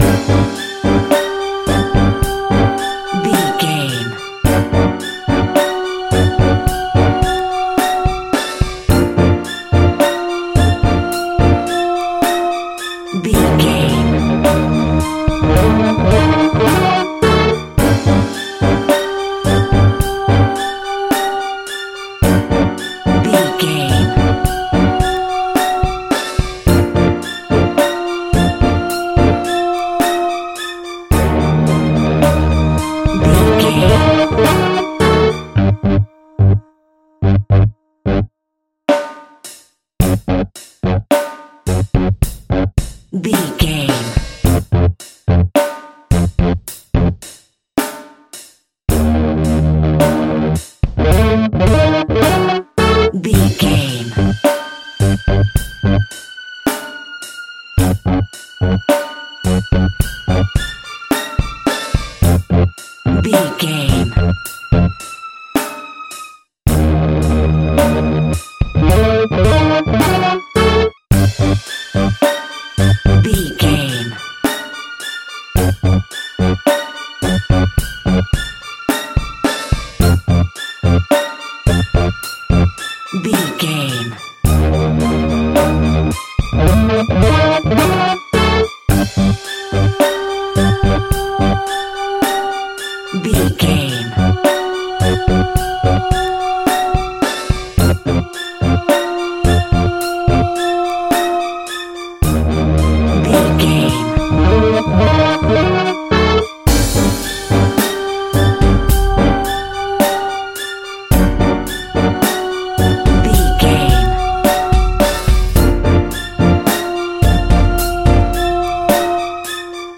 Aeolian/Minor
tension
ominous
dark
eerie
synthesizer
strings
drums
ambience
pads